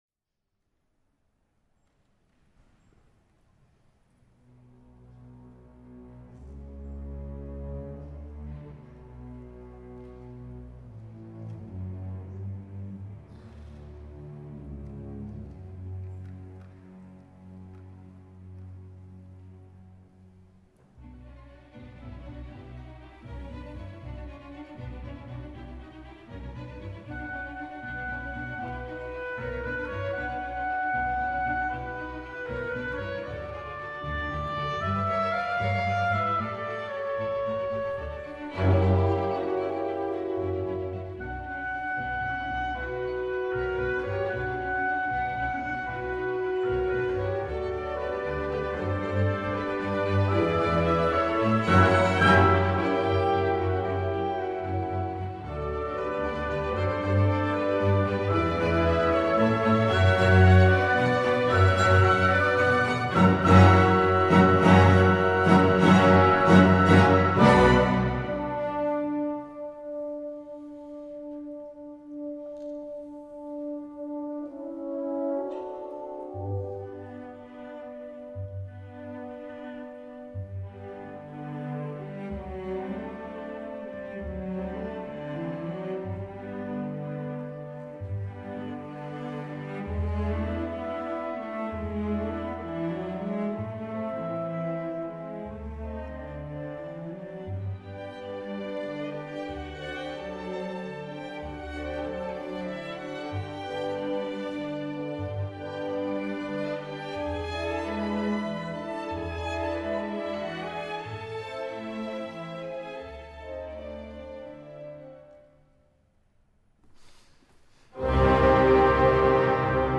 F. Schubert: Sinfonía nº 8 en Si Menor, D. 759
Saison Symphonique